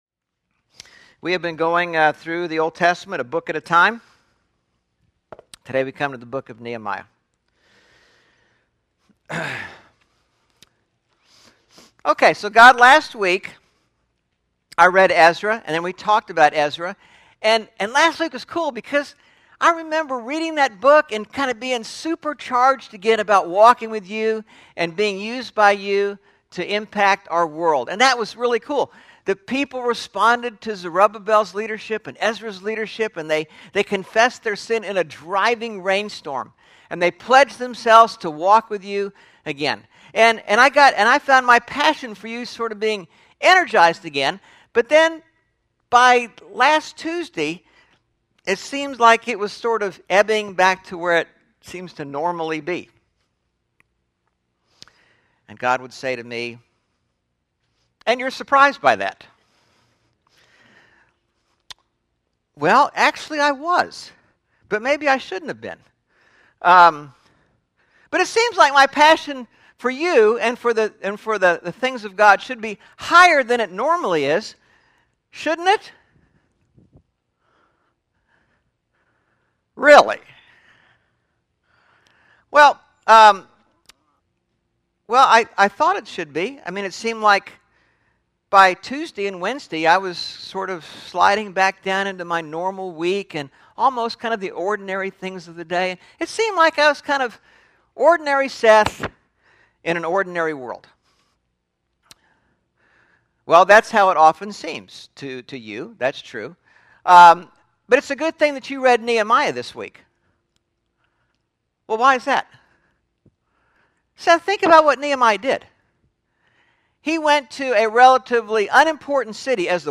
10/2/11 Sermon (What Should I Learn From Nehemiah?) – Churches in Irvine, CA – Pacific Church of Irvine